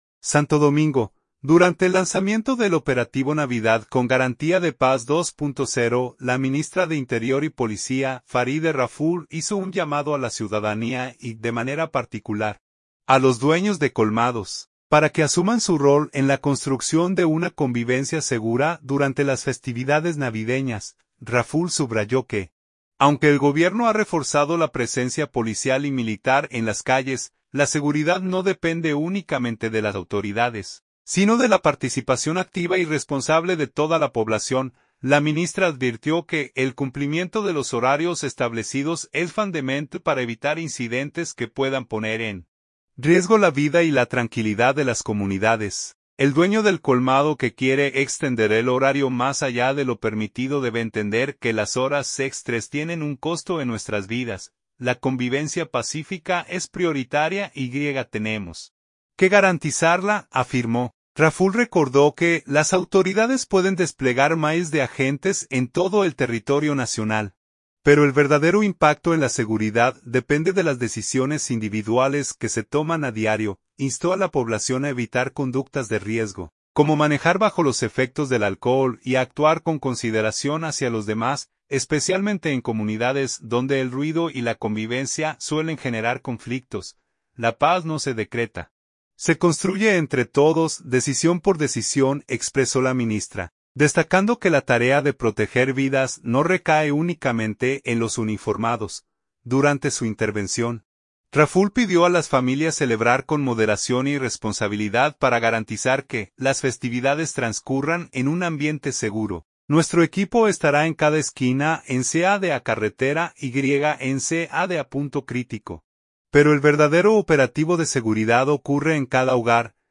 Santo Domingo. Durante el lanzamiento del operativo “Navidad con Garantía de Paz 2.0”, la ministra de Interior y Policía, Faride Raful, hizo un llamado a la ciudadanía y, de manera particular, a los dueños de colmados, para que asuman su rol en la construcción de una convivencia segura durante las festividades navideñas.